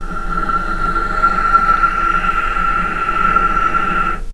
healing-soundscapes/Sound Banks/HSS_OP_Pack/Strings/cello/ord/vc-F6-pp.AIF at f6aadab7241c7d7839cda3a5e6764c47edbe7bf2
vc-F6-pp.AIF